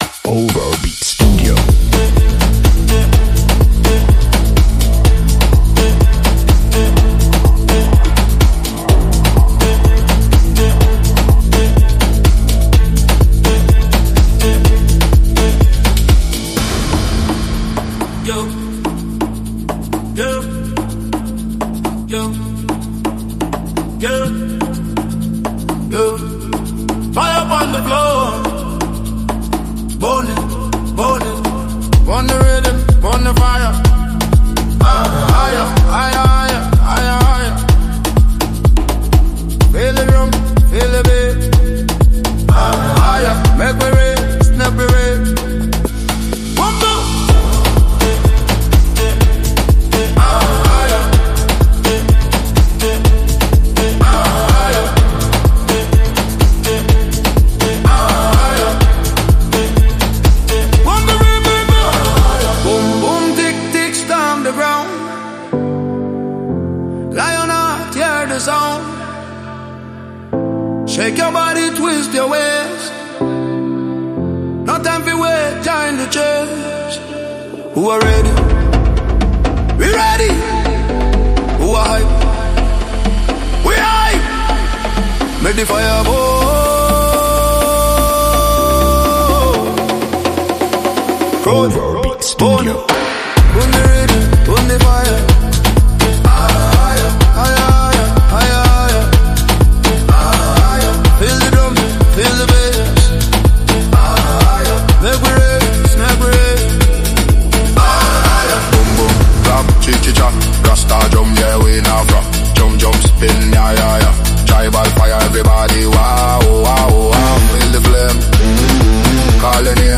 Qualità studio, download immediato e pagamento sicuro.